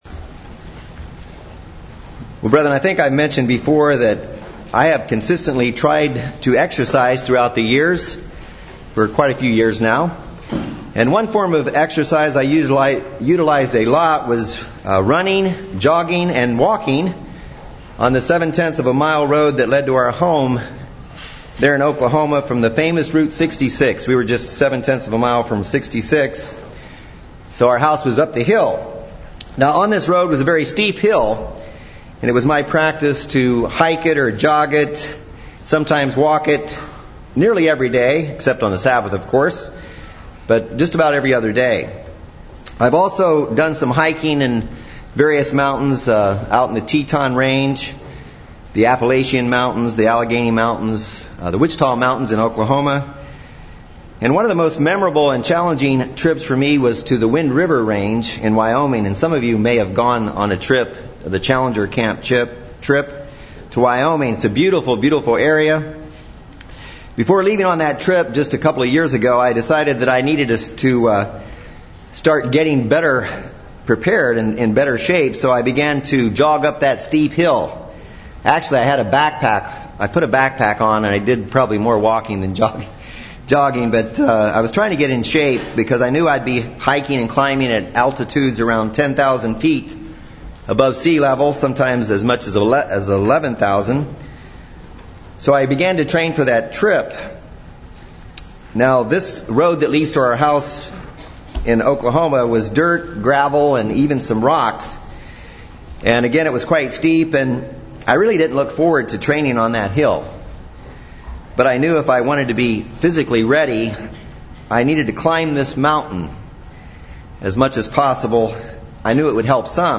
In this sermon we will discuss seven types of trials and the key to enduring and overcoming them all.